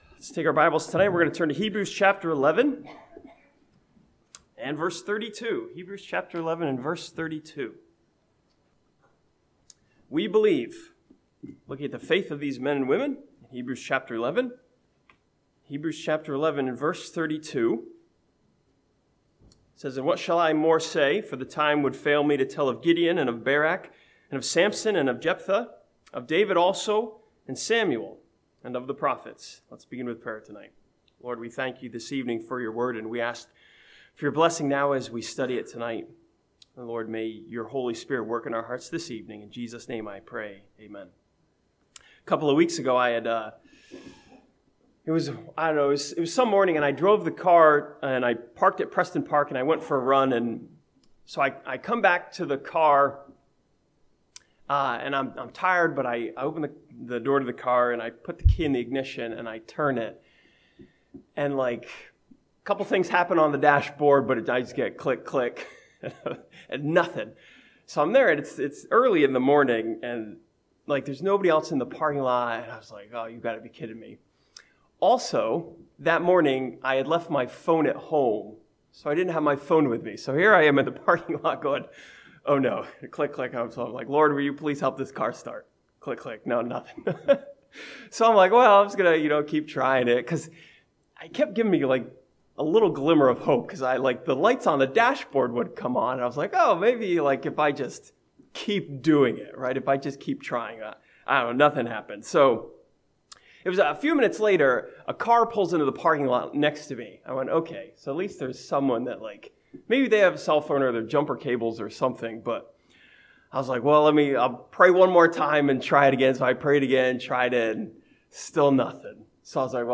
This sermon from Hebrews chapter 11 studies the faith of Samuel as he leads Israel to understand that God helps those that seek Him.